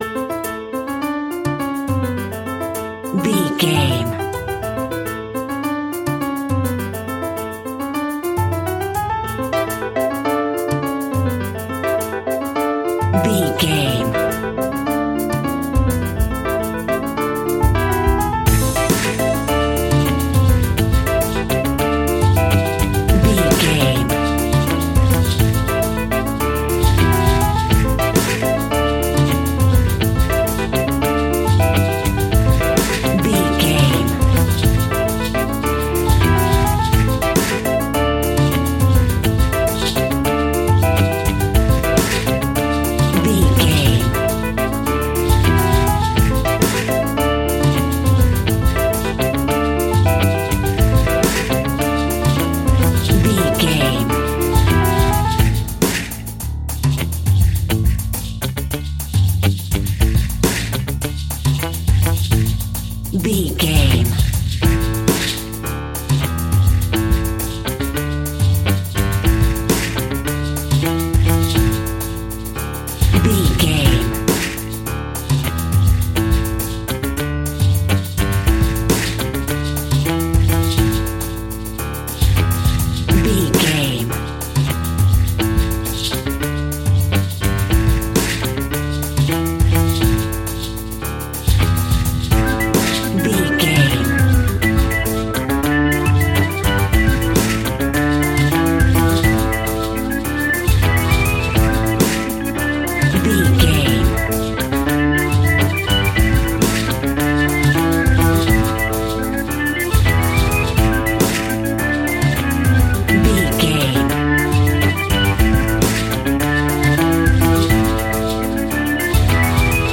Aeolian/Minor
maracas
percussion spanish guitar
latin guitar